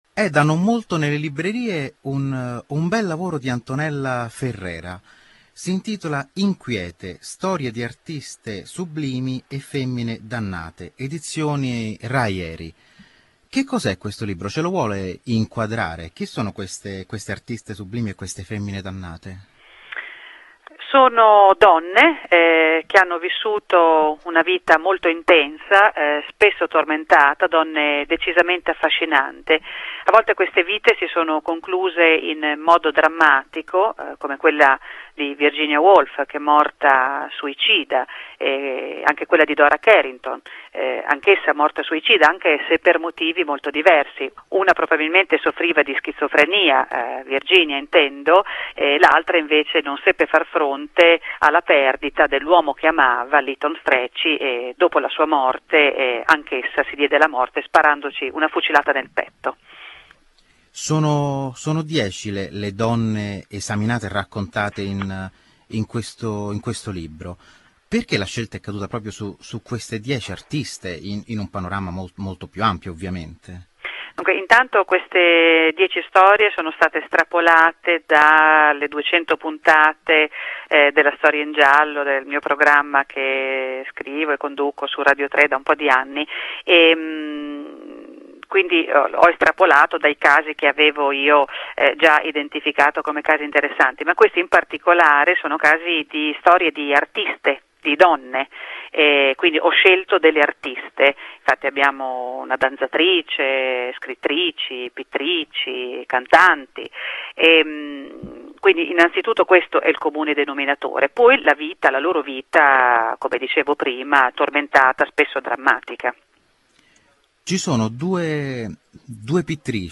Talento, tragedia, sregolatezza: una miscela esplosiva che ha dato vita e morte ad artiste sublimi e femmine dannate... Questi racconti nascono dal programma di Radio3 Rai "La storia in giallo", che ricostruisce in forma di sceneggiata storie personali e vicende misteriose e tormentate di grandi personaggi.